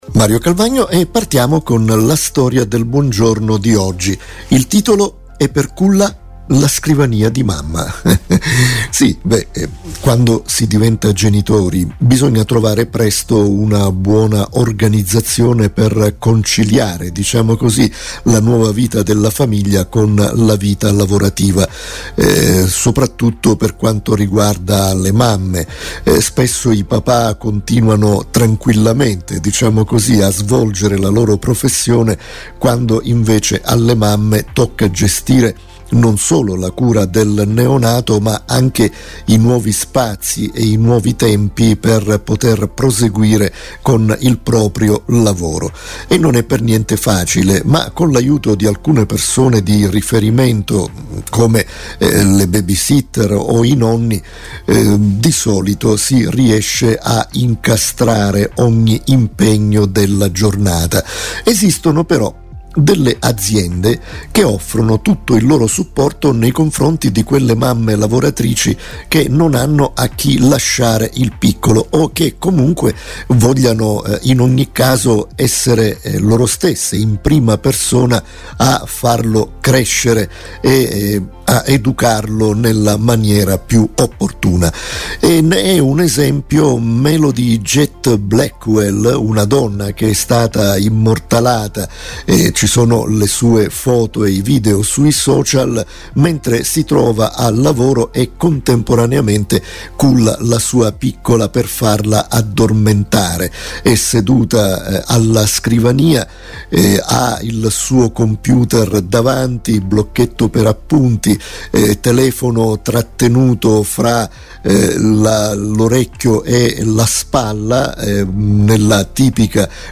Una storia commentata